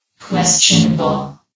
CitadelStationBot df15bbe0f0 [MIRROR] New & Fixed AI VOX Sound Files ( #6003 ) ...